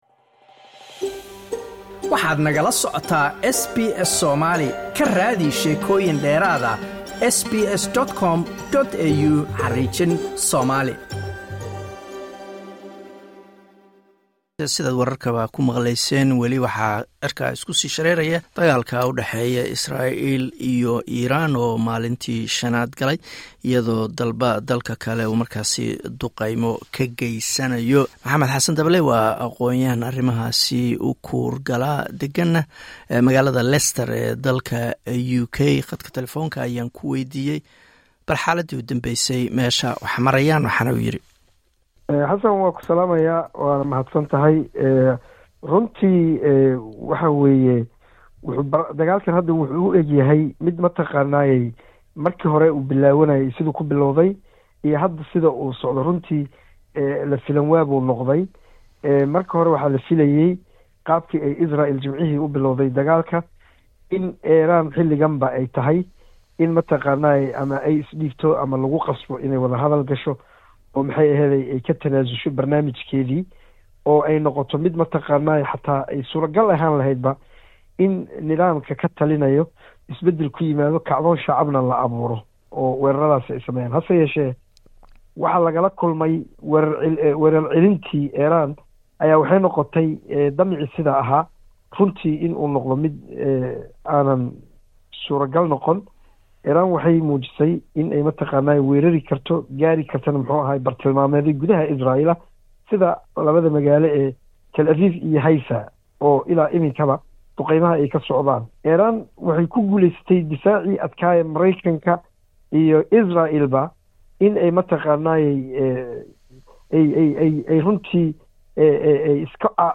Waraysi: xaaladda dagaalka Iran iyo Isreal